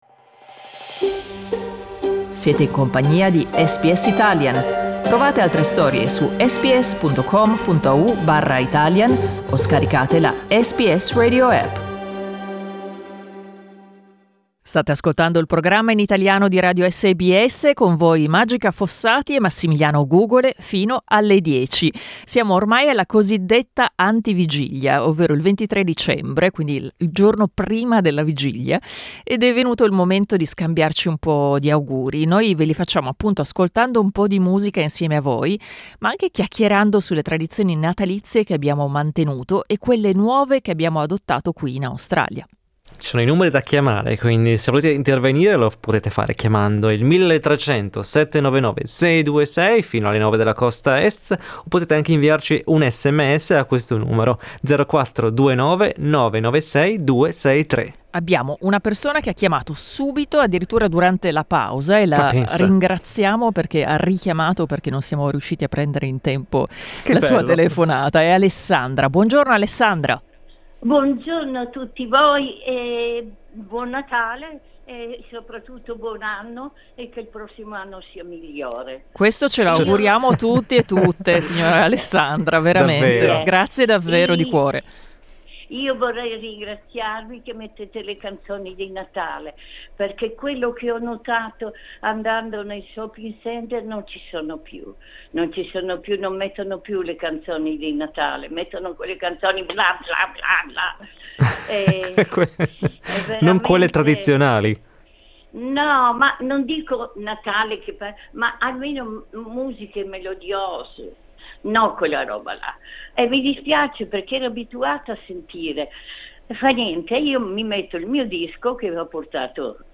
Noi ve li abbiamo fatti ascoltando un po' di musica insieme a voi e chiacchierando sulle tradizioni natalizie italiane che abbiamo mantenuto, e quelle nuove che abbiamo adottato qui in Australia.